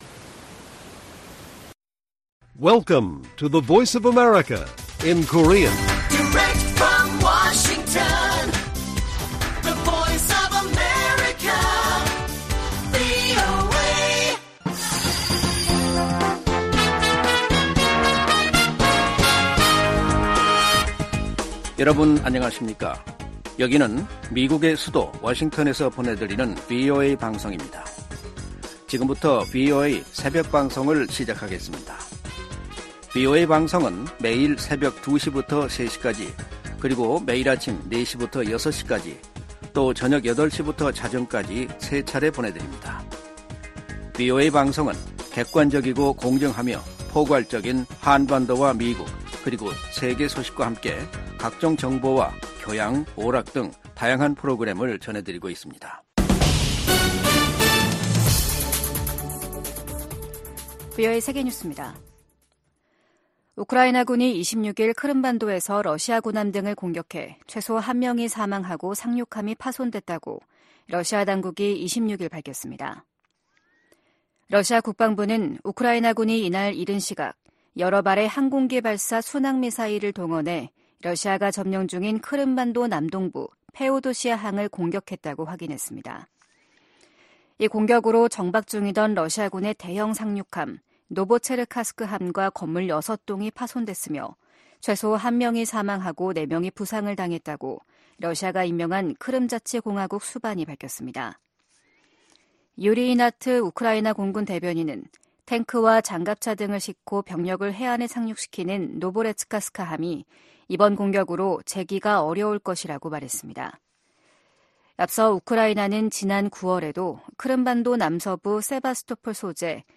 VOA 한국어 '출발 뉴스 쇼', 2023년 12월 27일 방송입니다. 조 바이든 대통령이 서명한 2024회계연도 미 국방수권법안에는 주한미군 규모를 현 수준으로 유지하는 내용과 한반도 관련 새 조항들이 담겼습니다. 북한이 영변의 실험용 경수로를 완공해 시운전에 들어간 정황이 공개되면서 한국 정부가 관련 동향을 예의주시하고 있습니다.